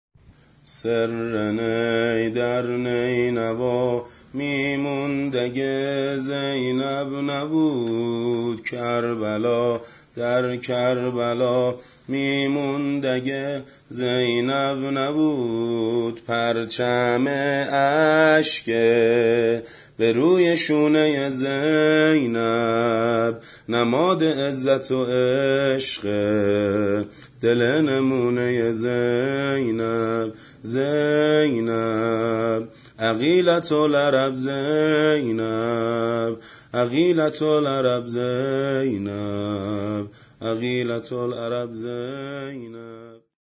زمینه حضرت زینب سلام الله علیها